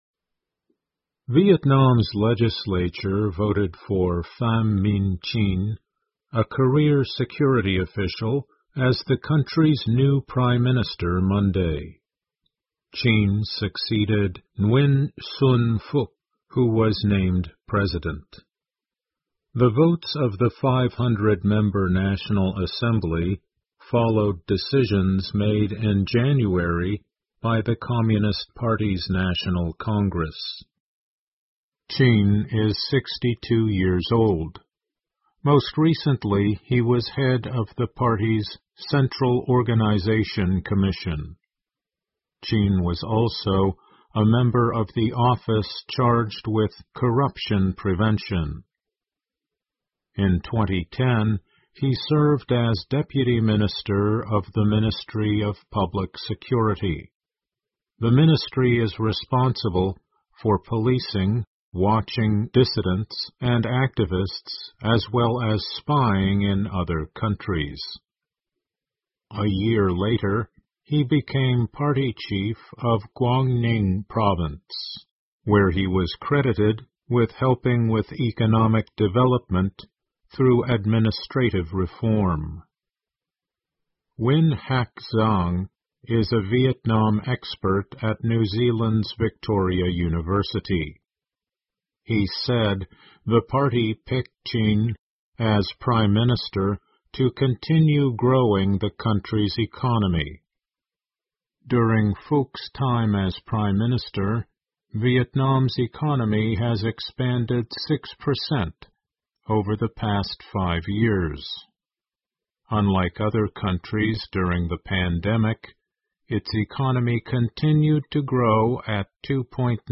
VOA慢速英语2021--越南前安全官员CHINH当选越南新任总理 听力文件下载—在线英语听力室